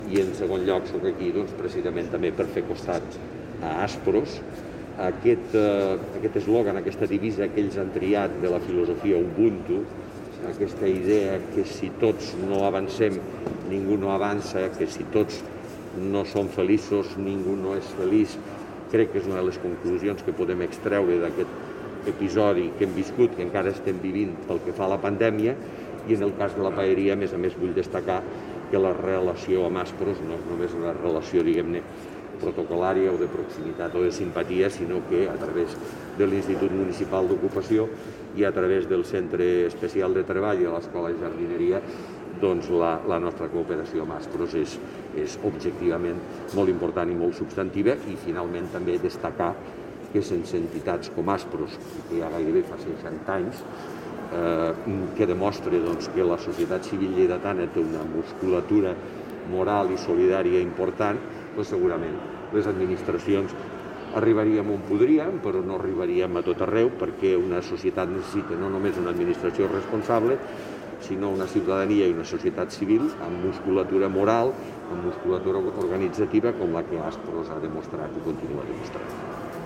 tall-de-veu-de-lalcalde-miquel-pueyo-sobre-el-consell-assessor-de-la-fundacio-aspros-presentat-avui-a-la-seu-vella